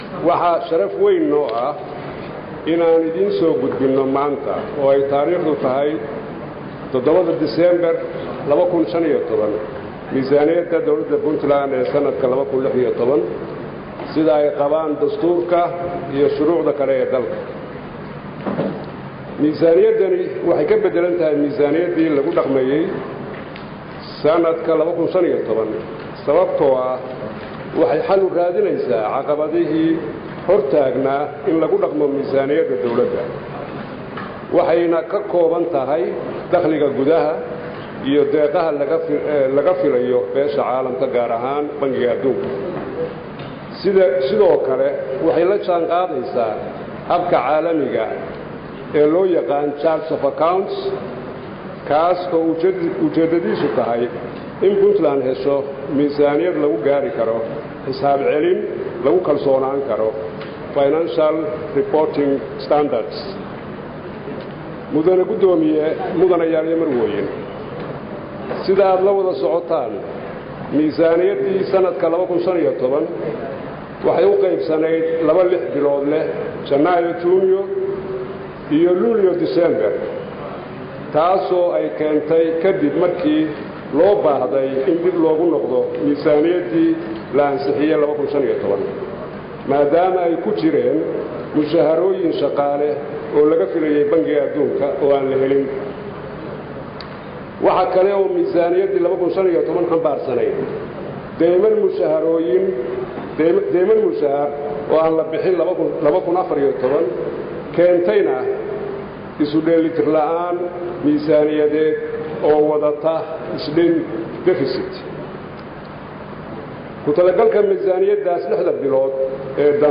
7 Dec 2015 (Puntlandes) Wasiirka Wasaaradda Maaliyadda Puntland Cabdullaahi Shiikh Axmed ayaa maanta u gudbiyey Baarlamaanka Puntland Miisaaniyadda dawladda Puntland ee 2016ka, isagoona ka hor akhriyey Baarlamaanka  miisaaniyada dowladda lixdii bilood dambe ee sanadkan.
Dhagayso Wasiirka